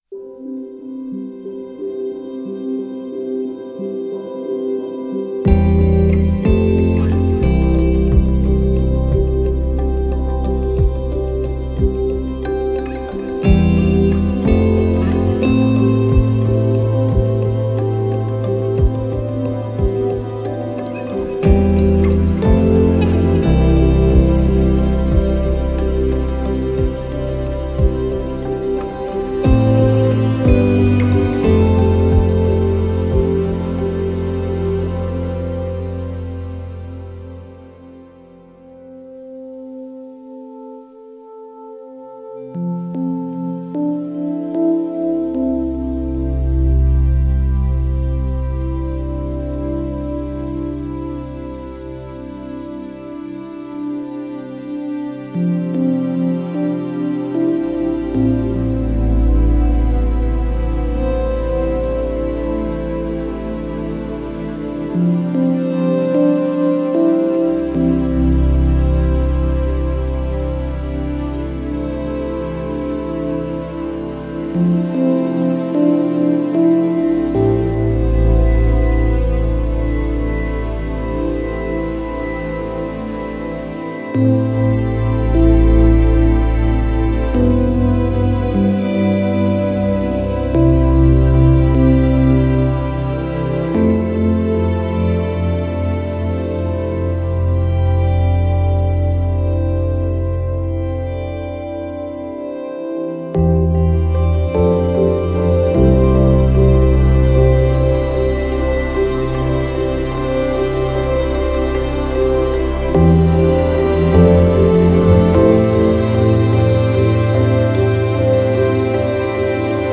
Genre: Score.